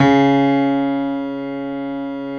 55p-pno14-C#2.wav